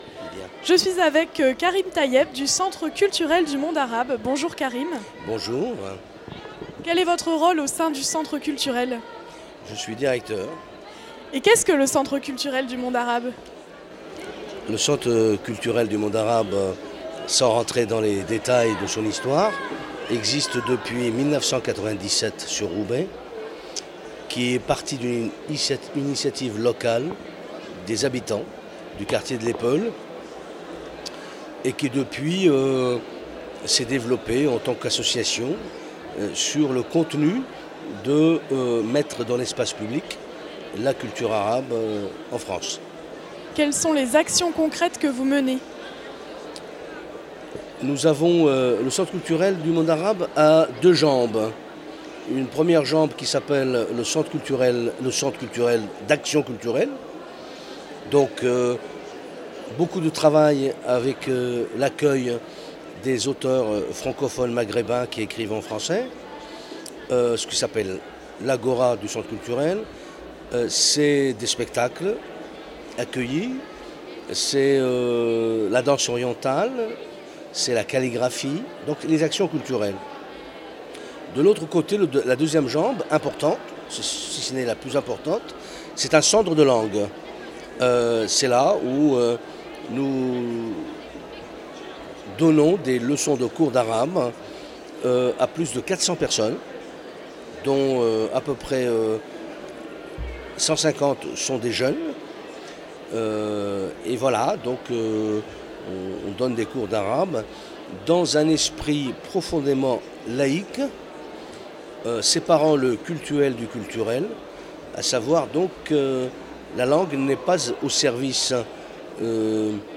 à la CCI de Lille
Interviews réalisées pour Radio Campus